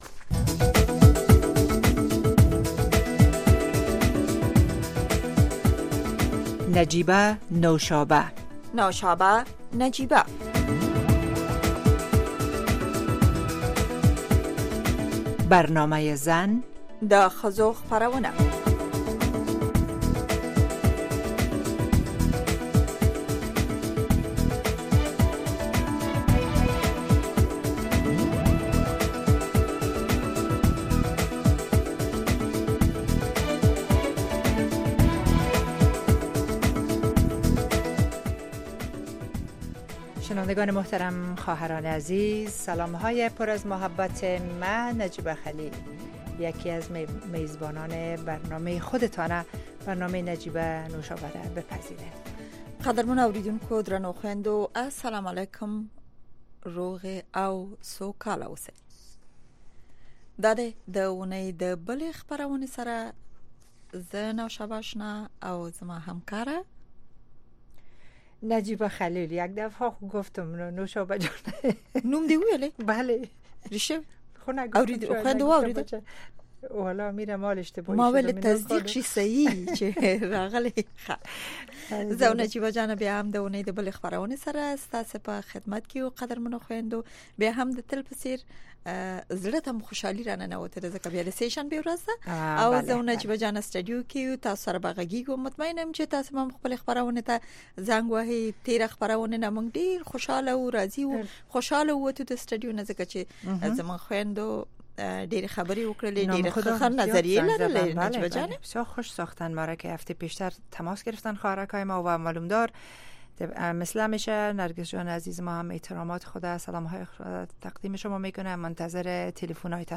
گفت و شنود - خبرې اترې، بحث رادیویی در ساعت ۰۸:۰۰ شب به وقت افغانستان به زبان های دری و پشتو است. در این برنامه، موضوعات مهم خبری هفته با حضور تحلیلگران و مقام های حکومت افغانستان به بحث گرفته می شود.